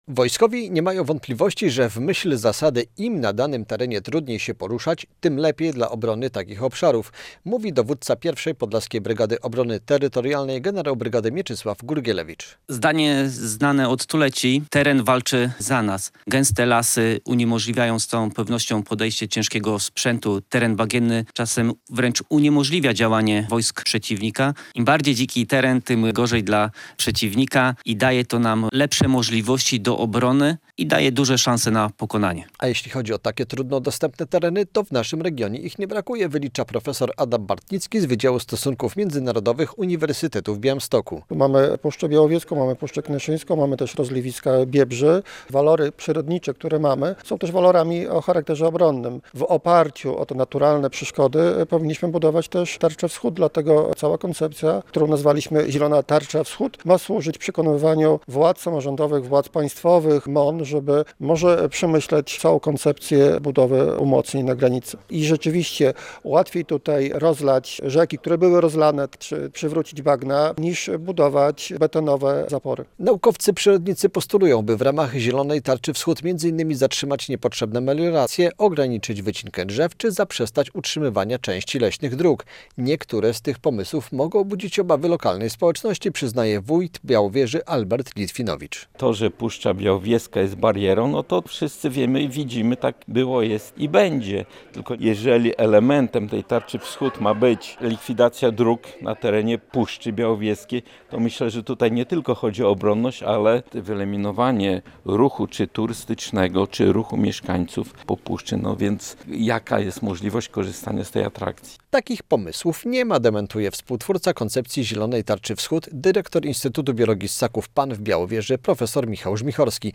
Radio Białystok | Wiadomości | Wiadomości - Koncepcja Zielonej Tarczy Wschód tematem seminarium naukowego w Białowieży